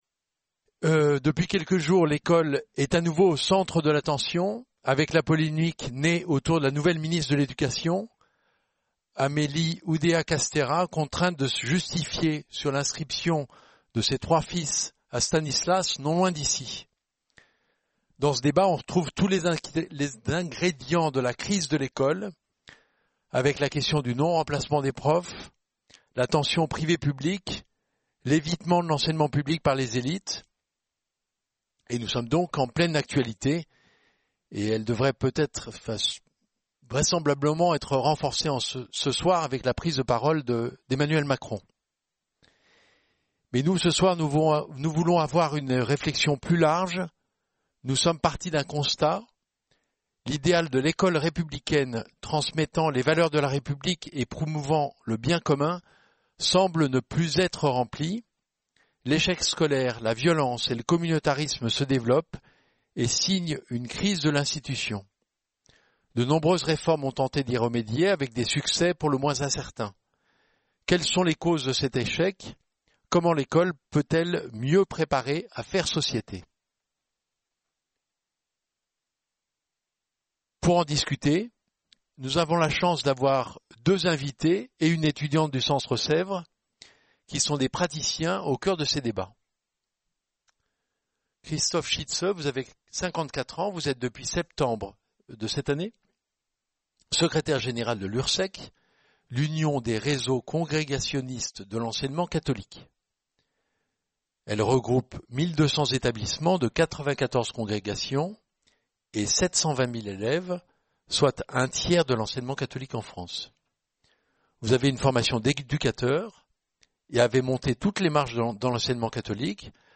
Débat animé par